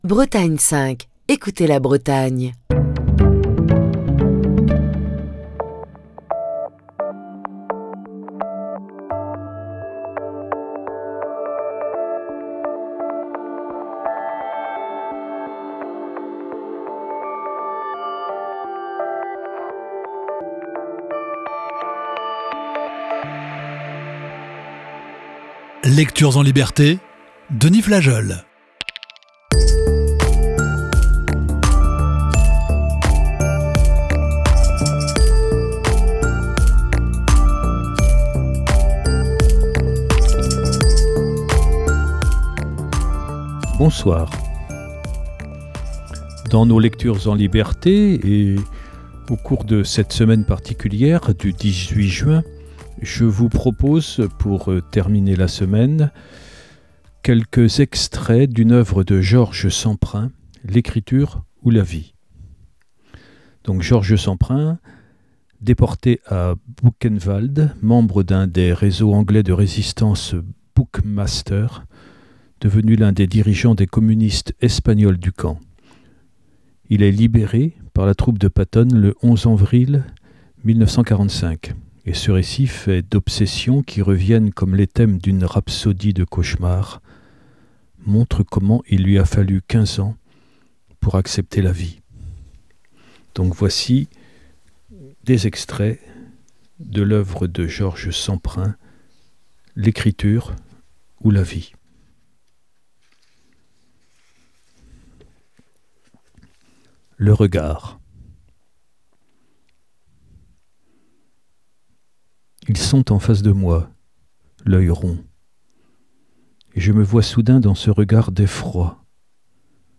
Émission du 9 novembre 2023.